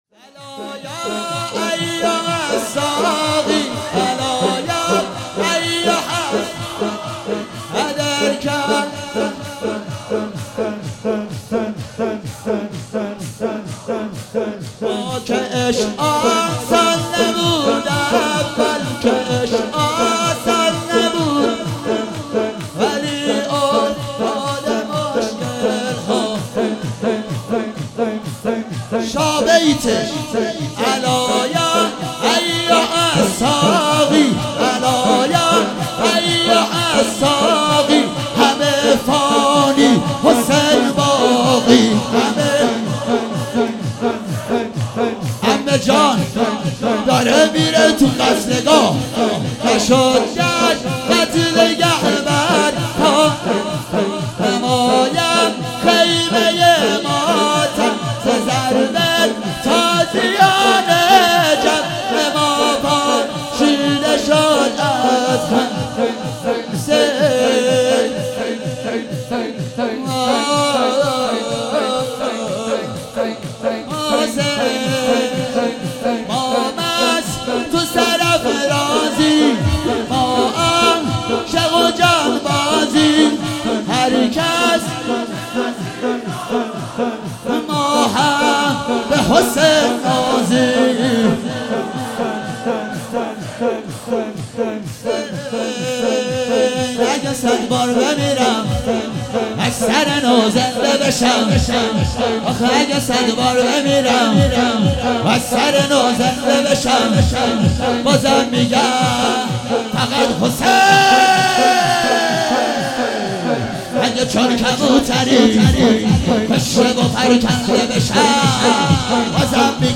شور - الا یا ایها الساقی ادر کاسا